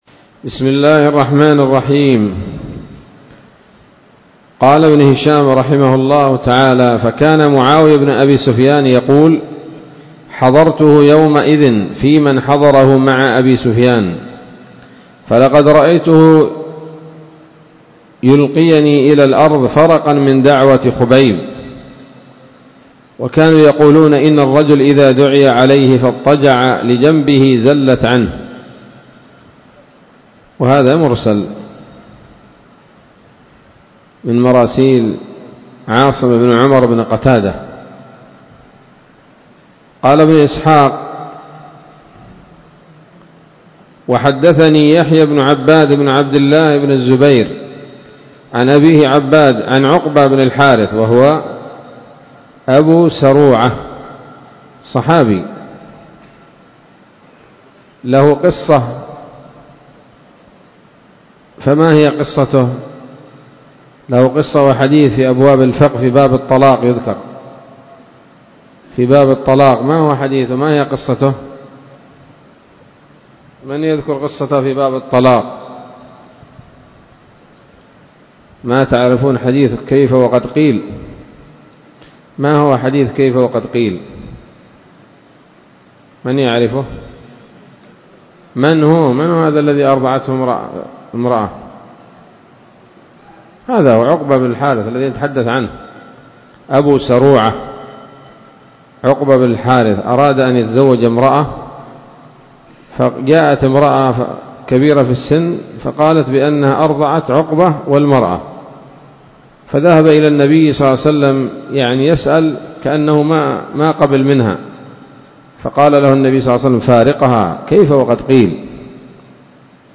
الدرس الرابع والثمانون بعد المائة من التعليق على كتاب السيرة النبوية لابن هشام